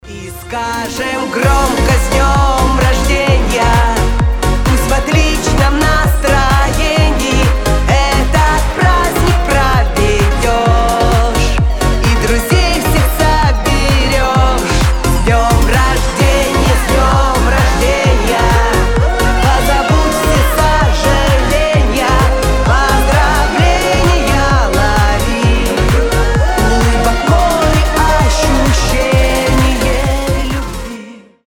Танцевальные рингтоны
Рингтоны шансон
Позитивные
Душевные
Поп